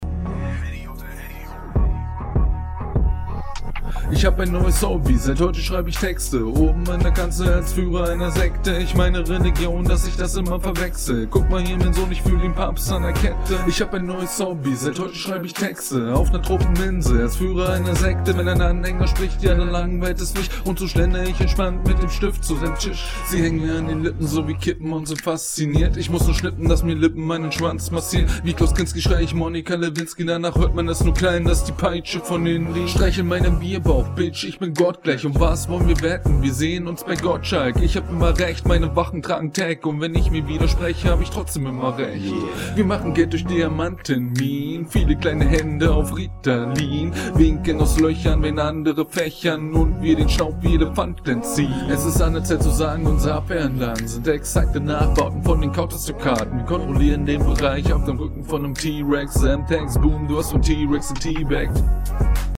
(Schreibaufnahme)